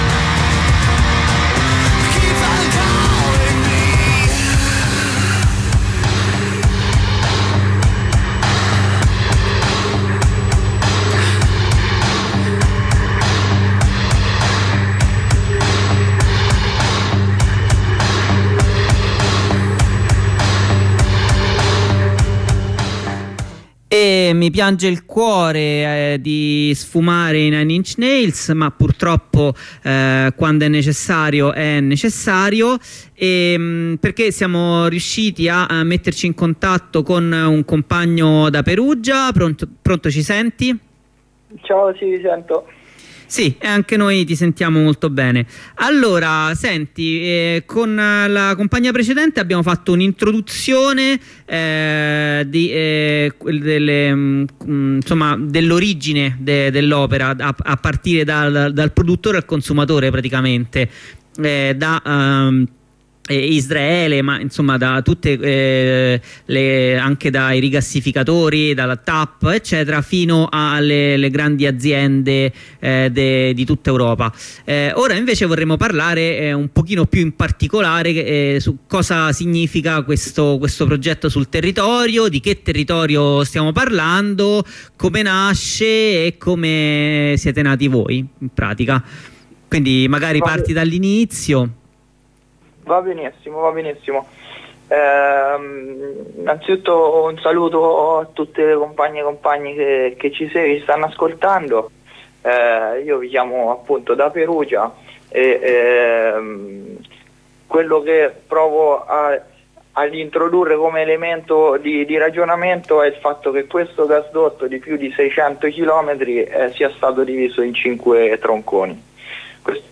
Telefonata con coordinamento ternano per la palestina